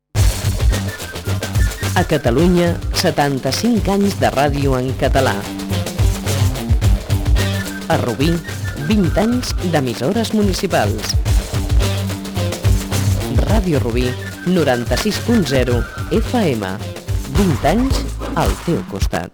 Indicatiu de l'emissora en el seu 20è aniversari